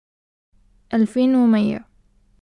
[ alfeen(e) w meyya ]